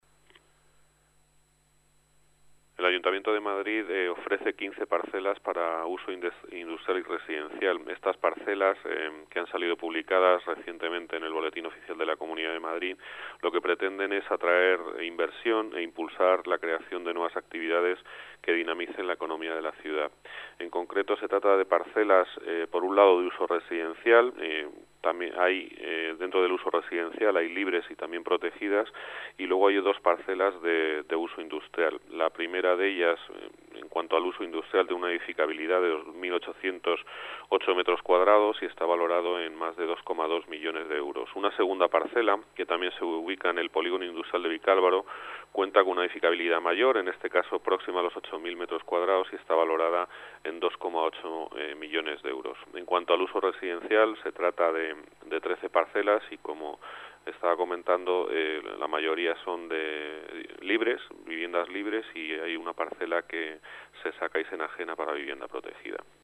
Nueva ventana: Declaraciones de Juan José de Gracia, coordinador general de Gestión Urbanística, Vivienda y Obras